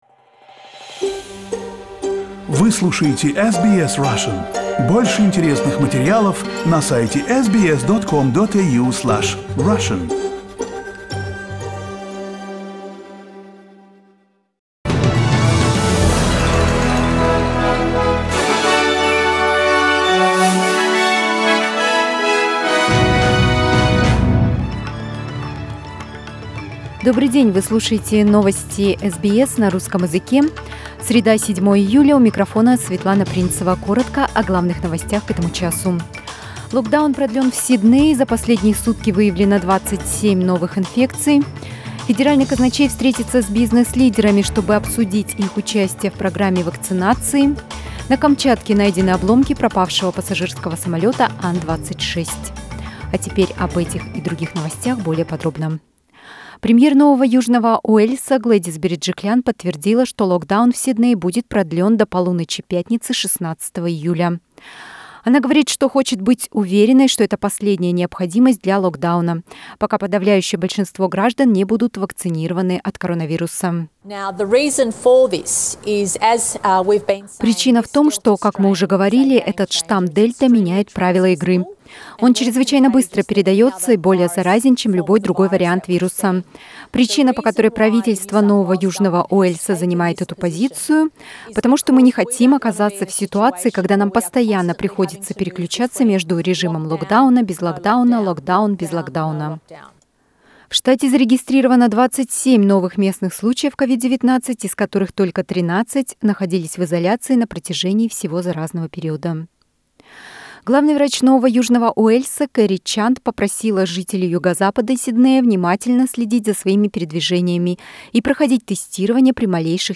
Новости SBS на русском языке - 7.07